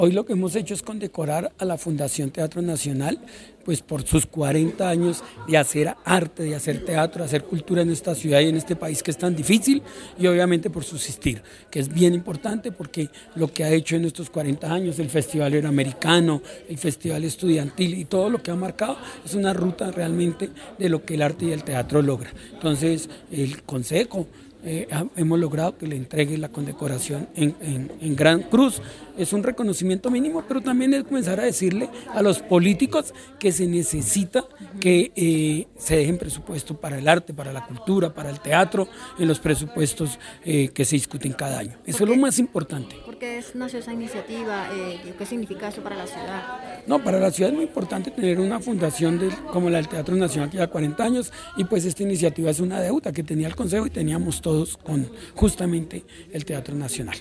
Concejal de Bogotá partido Liberal Venus Albeiro Silva, proponente